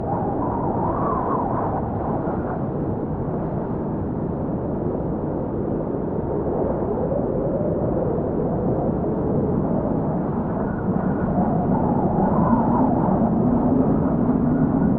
Dust Storm
Dust Storm is a free nature sound effect available for download in MP3 format.
# dust # storm # desert # wind About this sound Dust Storm is a free nature sound effect available for download in MP3 format.
600_dust_storm.mp3